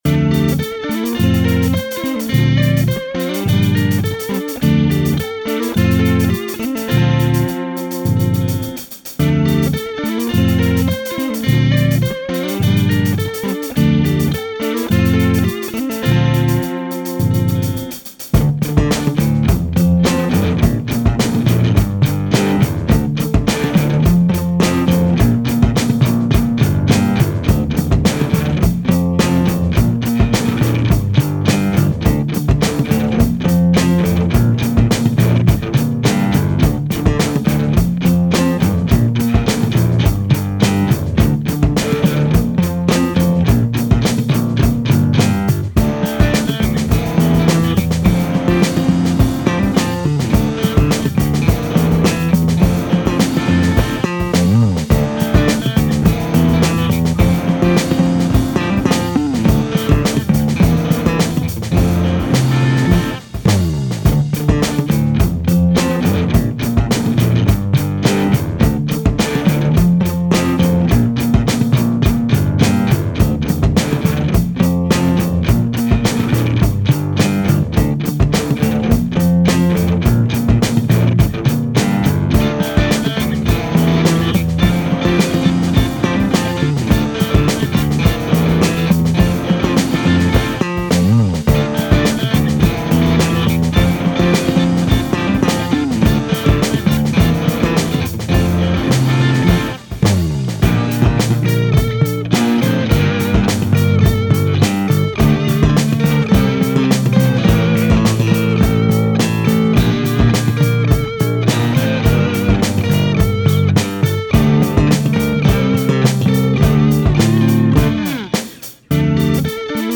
Ein Sandberg Electra VS 4.
Auf dem Ding hab ich, was nicht meiner Natur ist, so Slap Triplets gespielt, ohne das es absolut scheisse klang.
Dieser Bass hat mich dazu gebracht eine E-Gitarre zu kaufen, damit ich einen Slapbass selbst begleiten kann.